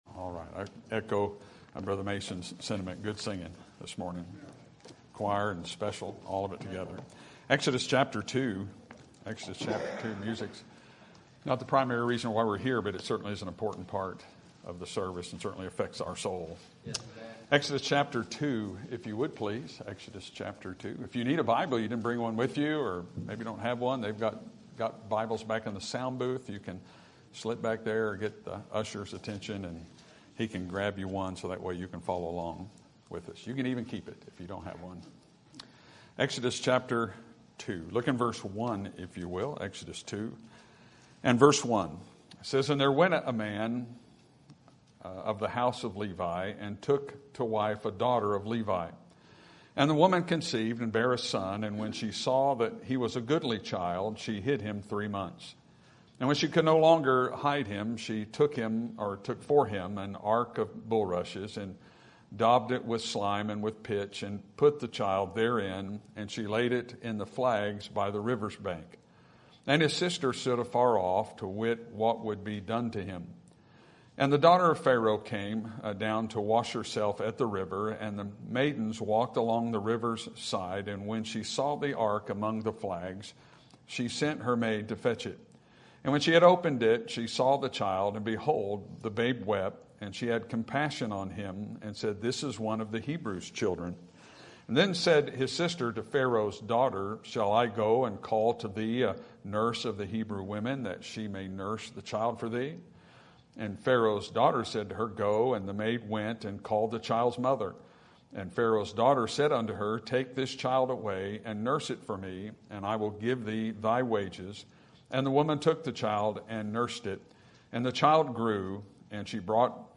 Sermon Topic: General Sermon Type: Service Sermon Audio: Sermon download: Download (22.43 MB) Sermon Tags: Exodus Mother Moses Jochebed